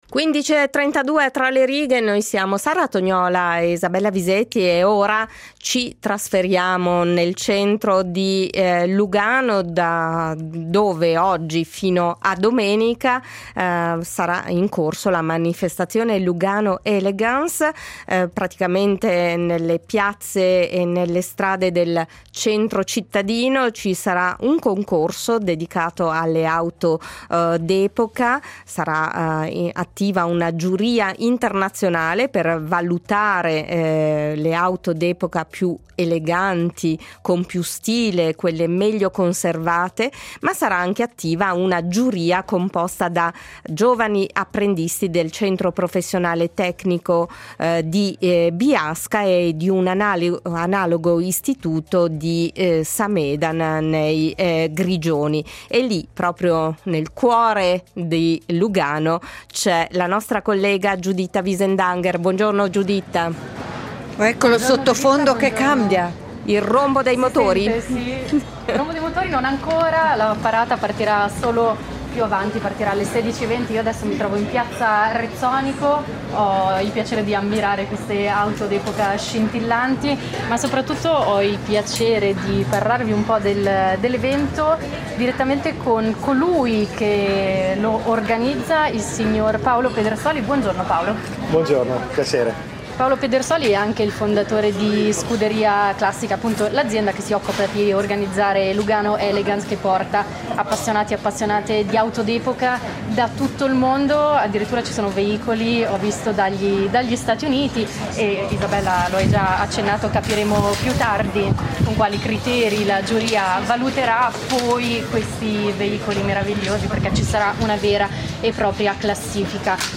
Da oggi fino a domenica Lugano ospiterà un concorso dedicato alle auto d’epoca, dove sarà attiva anche una giuria composta da apprendisti del Centro professionale tecnico di Biasca e dell’analogo istituto a Samedan (GR). Con collegamenti dal centro di Lugano, la voce degli studenti confrontati con questa esperienza pratica e con riflessioni sui canoni dell’eleganza.